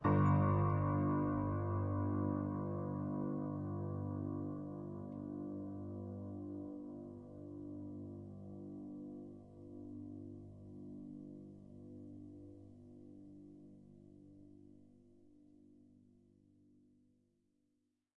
描述：记录了一架GerardAdam钢琴，它至少有50年没有被调音了！
Tag: 失谐 恐怖 踏板 钢琴 弦乐 维持